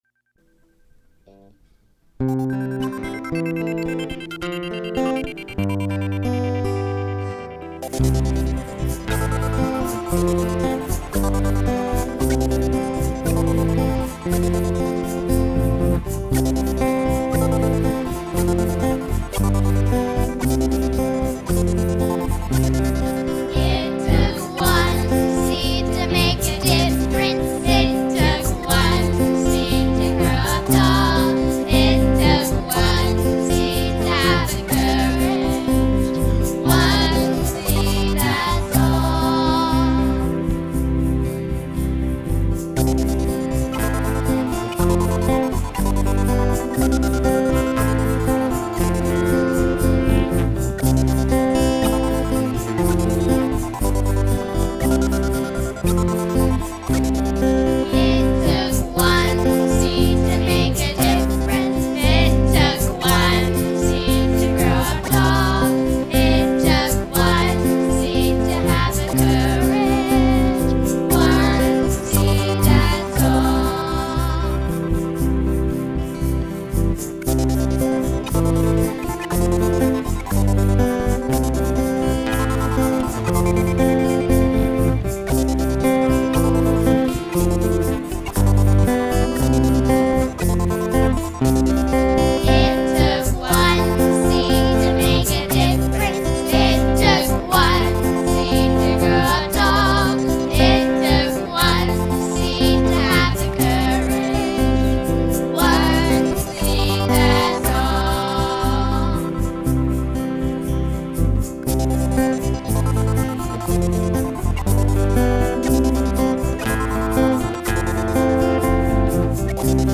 Instrumental Only with Children